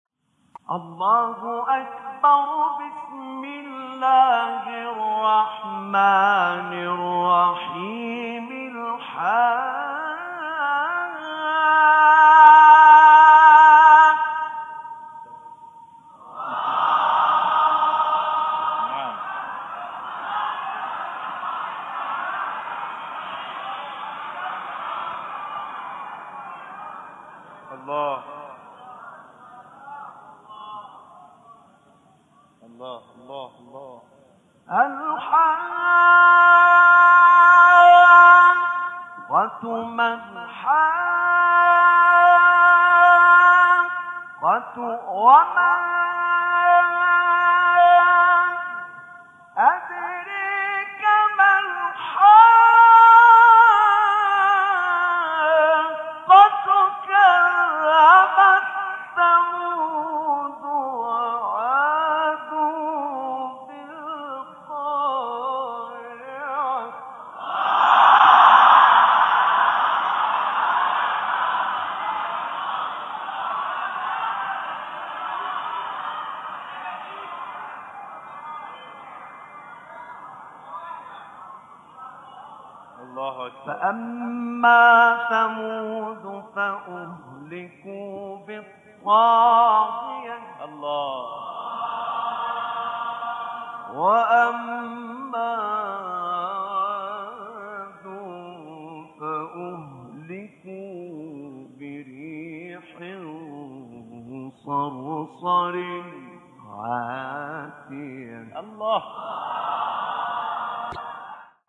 آیه 1-6 سوره حاقه استاد نعینع | نغمات قرآن | دانلود تلاوت قرآن